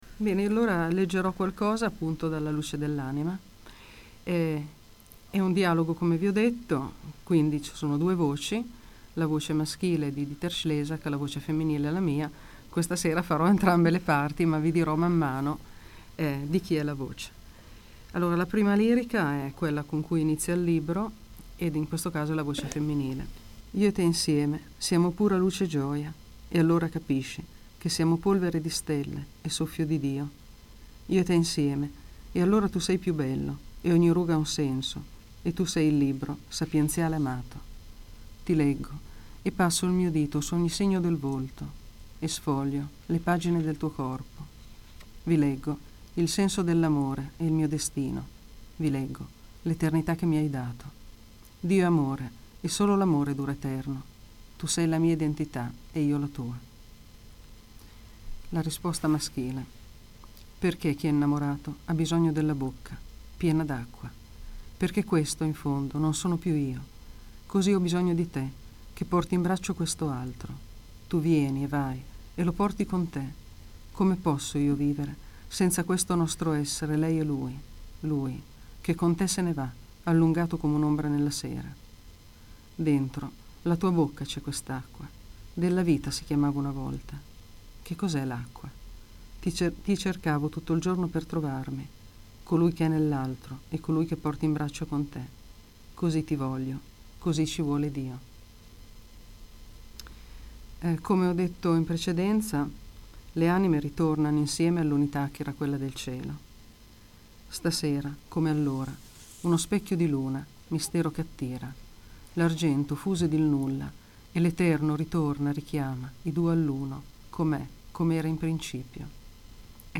Intervista/reading